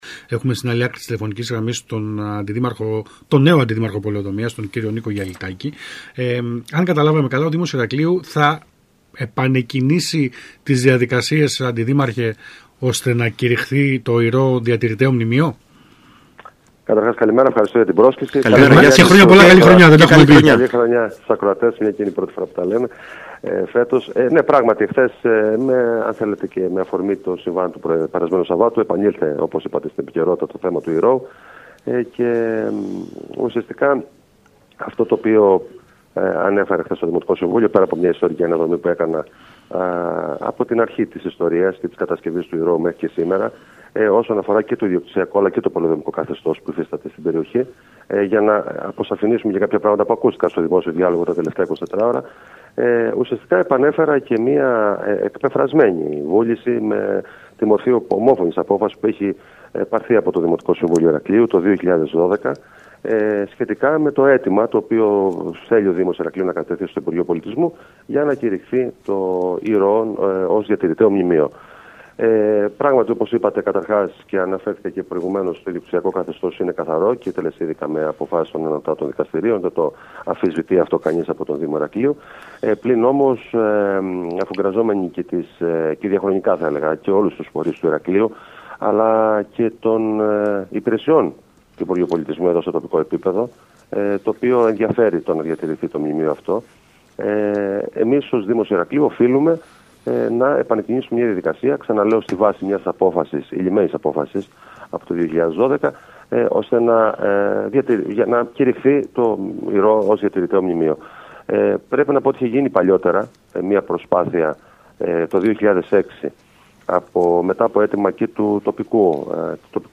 Για το θέμα μίλησε στον ΣΚΑΙ Κρήτης 92.1 ο Αντιδήμαρχος Πολεοδομίας Νίκος Γιαλιτάκης, ο οποίος επιβεβαίωσε ότι είναι στις προθέσεις της δημοτικής αρχής να το ανοίξει ξανά.